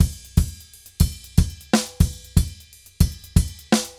Drums_Baion 120_3.wav